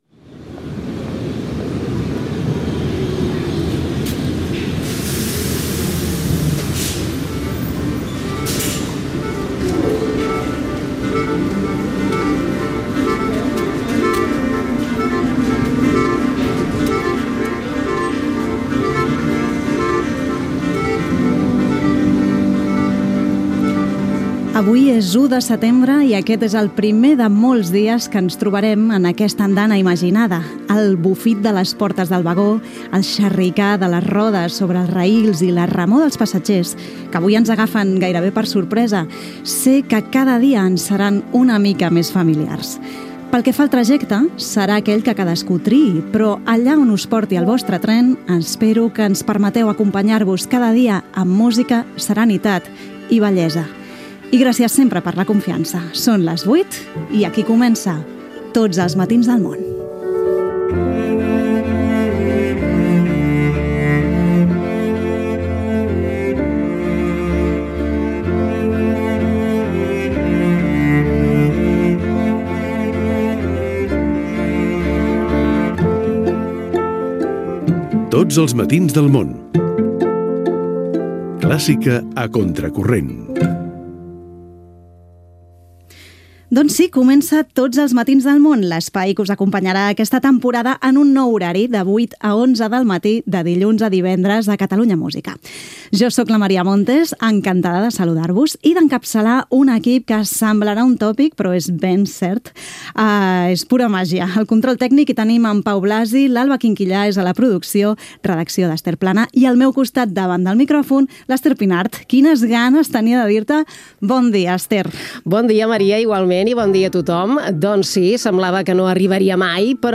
Inici del primer programa de la temporada 2025-2026. Data, invitació a l'escolta, hora, careta, comentari sobre el canvi d'horari del programa. Sumari. Nous col·laboradors